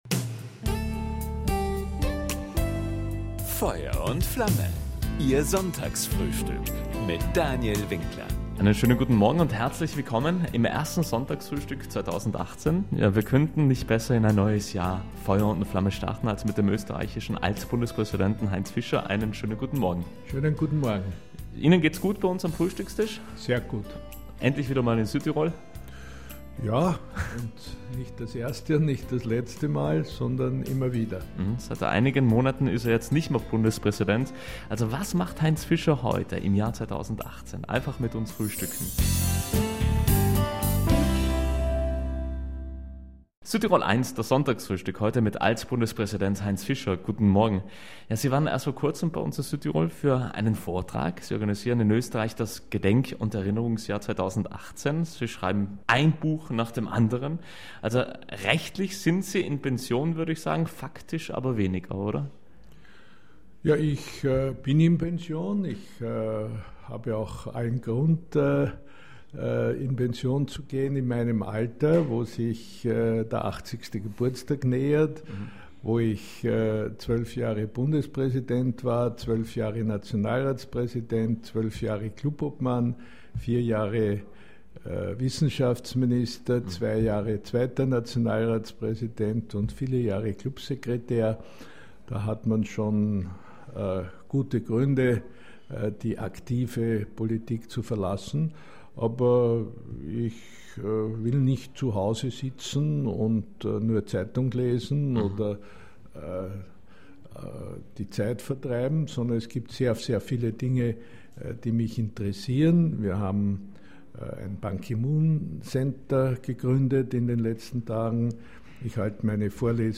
Beim Sonntagsfrühstück auf Südtirol 1 plauderte der ehemalige Spitzenpolitiker nicht nur über sein politisches Engagement der vergangenen Jahrzehnte, sondern gewährte auch Einblicke in sein Leben abseits der politischen Bühne.